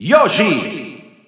Announcer saying Yoshi's name in Spanish from Super Smash Bros. 4 and Super Smash Bros. Ultimate
Yoshi_Spanish_Announcer_SSB4-SSBU.wav